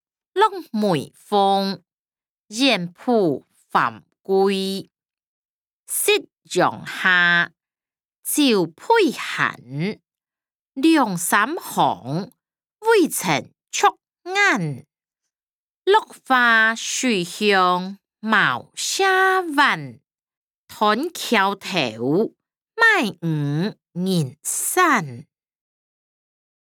詞、曲-落梅風．遠浦帆歸音檔(大埔腔)